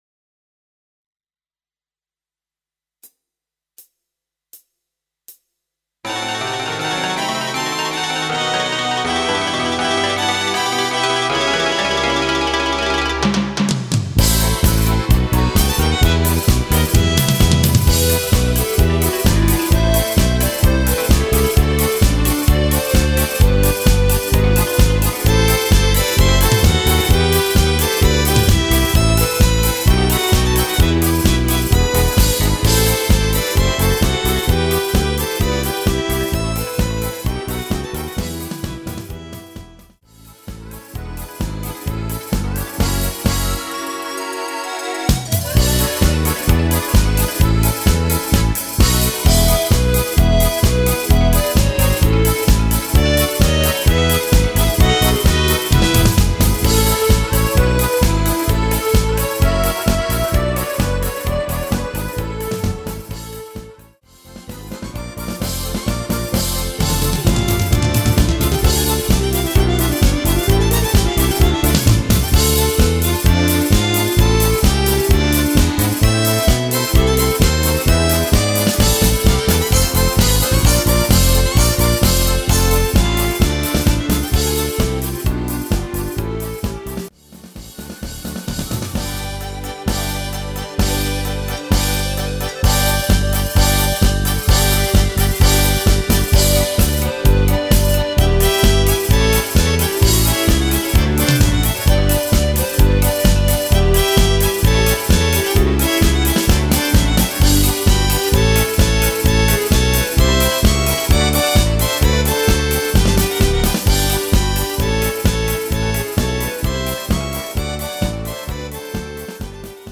Rubrika: Národní, lidové, dechovka
- směs - cimbálovka